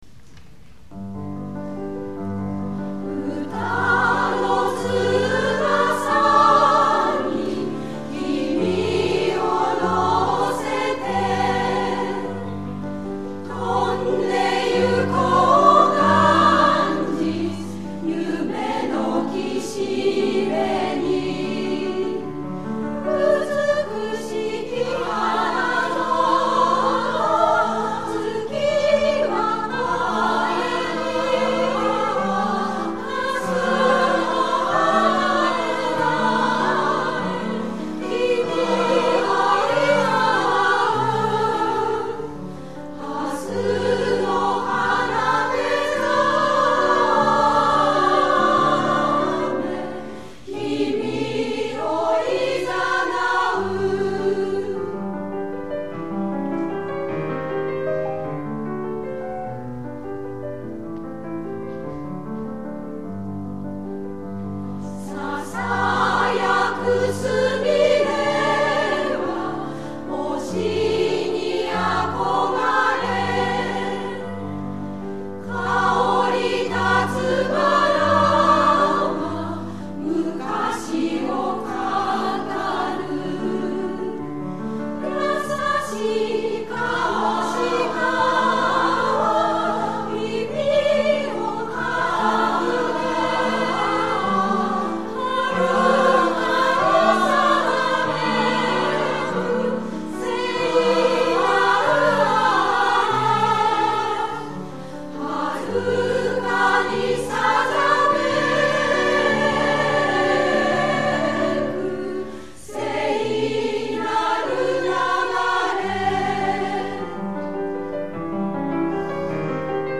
女声合唱団「道」 お知らせ
女声合唱団「道」の第３回コンサートを平成１９年６月１６日（土）に開きました。